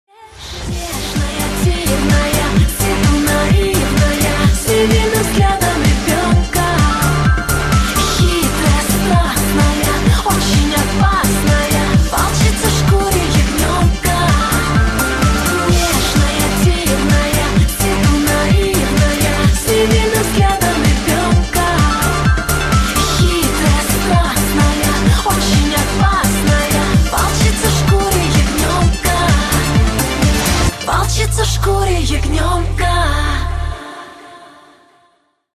• Качество: 128, Stereo
поп
русская попса